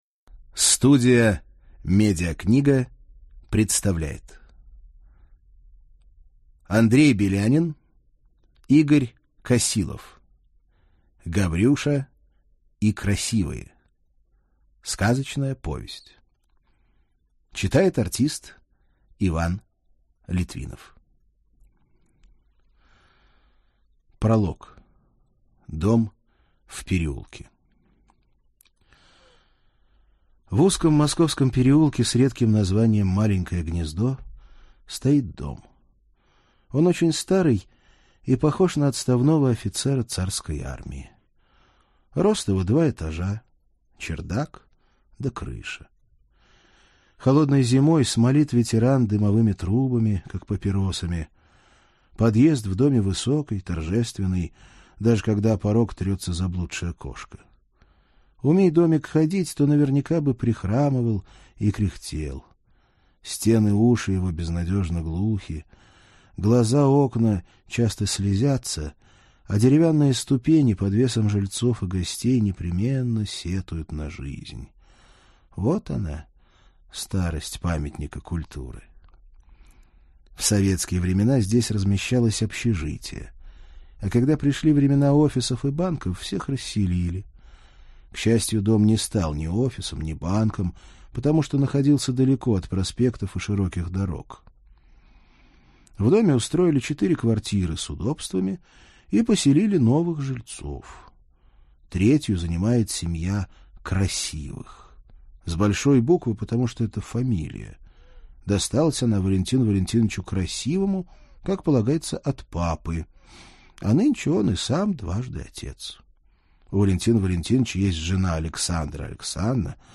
Аудиокнига Гаврюша и Красивые | Библиотека аудиокниг